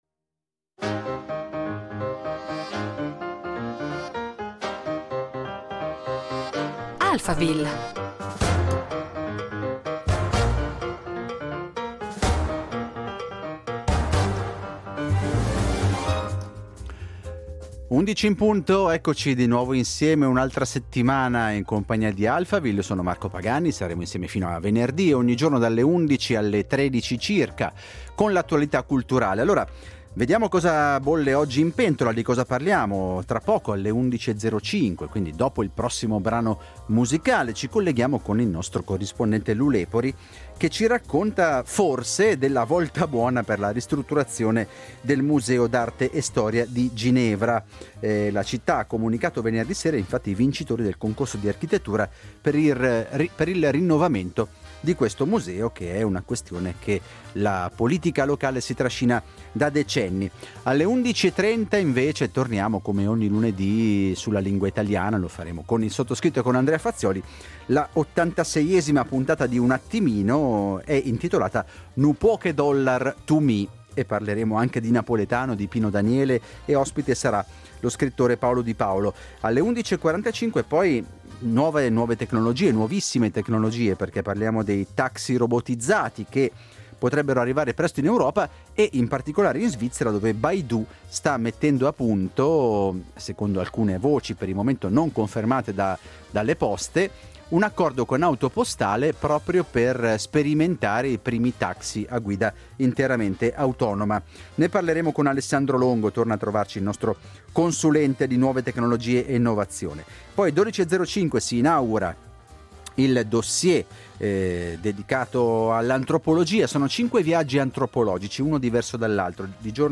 facciamo il punto con il nostro corrispondente